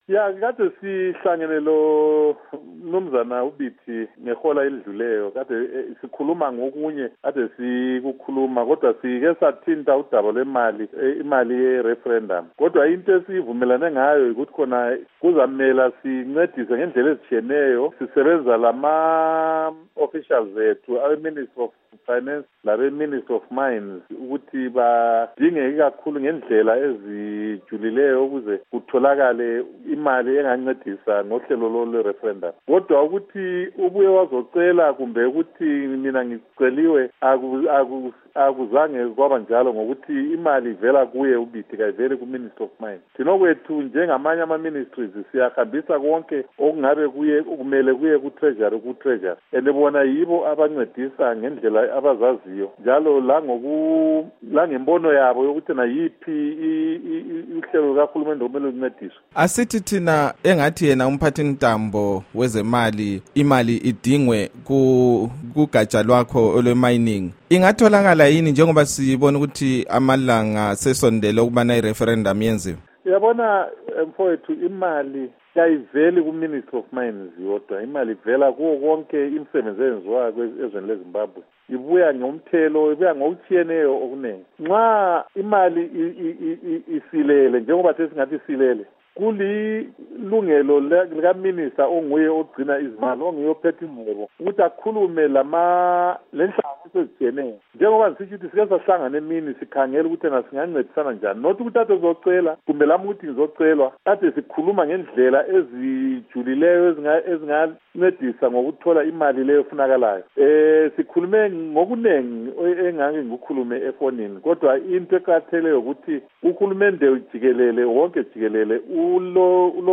Ingxoxo loMnu Obert Mpofu ngodaba lwemali yeReferendum